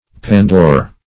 Pandoor \Pan"door\, n.